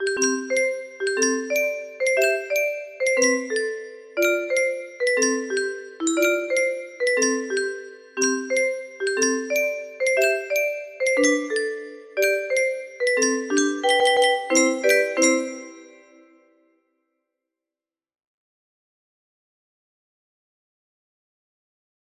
30118 music box melody
Hey! It looks like this melody can be played offline on a 20 note paper strip music box!